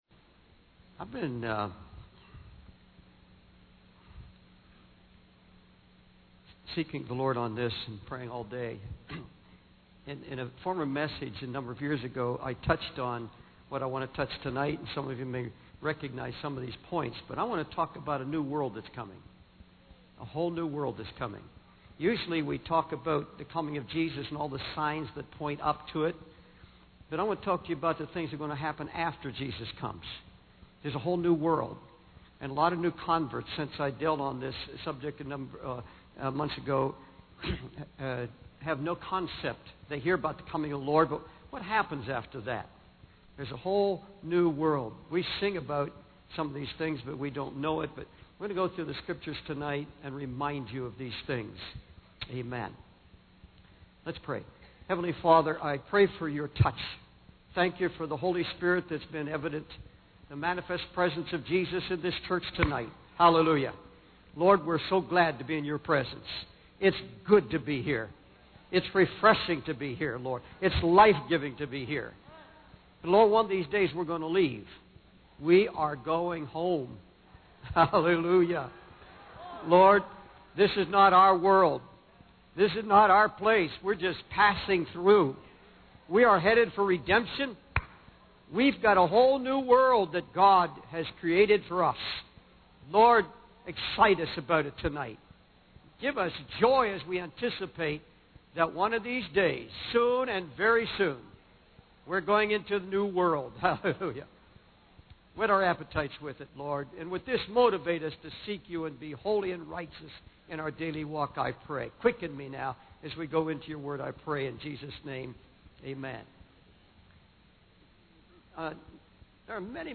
In this sermon, the preacher emphasizes the importance of having joy and confidence in one's Christian life.